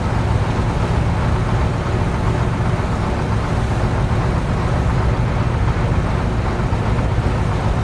train-engine-1.ogg